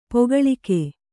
♪ pogaḷike